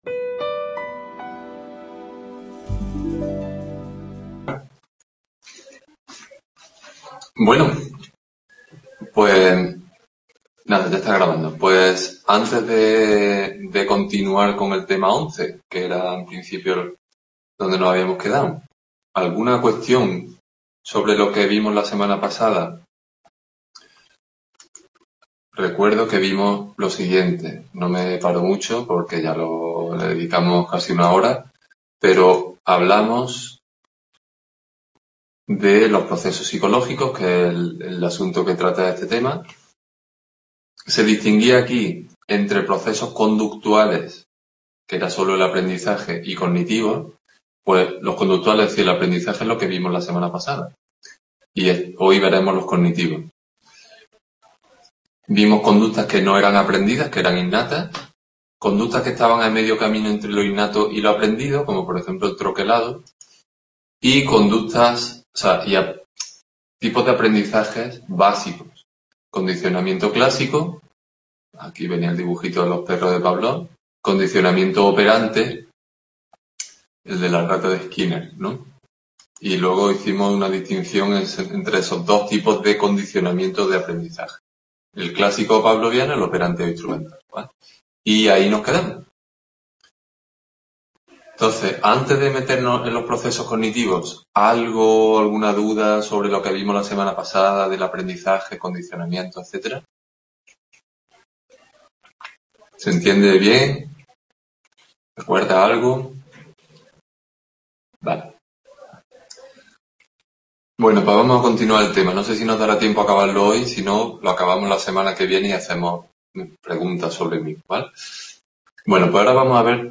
Los procesos Description Tutoría de Psicología del Curso de Acceso en Córdoba. Tema 11 segunda parte. Procesos cognitivos.